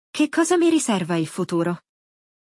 1. Escute atentamente o diálogo – Preste atenção às palavras e à entonação dos falantes nativos.
É uma série de aulas de áudio focadas na aprendizagem do italiano por meio da repetição guiada e explicações didáticas.